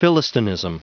Prononciation du mot philistinism en anglais (fichier audio)
Prononciation du mot : philistinism